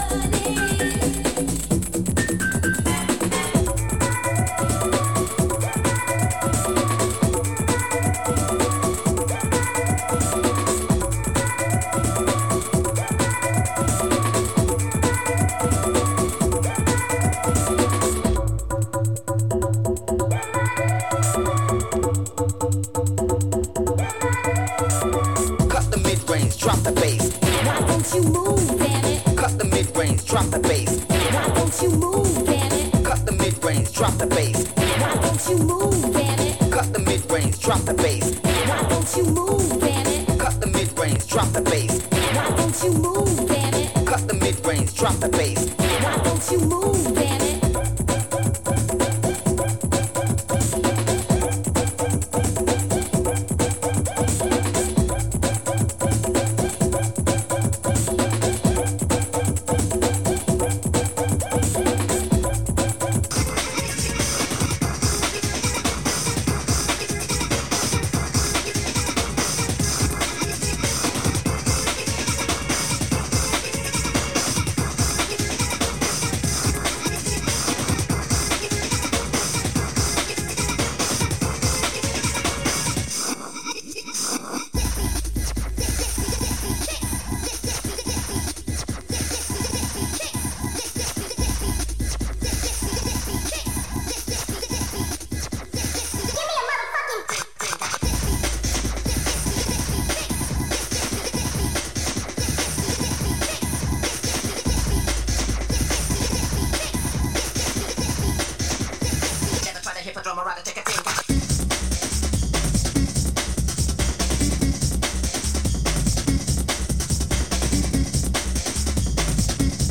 Categories: Breakbeat , Hardcore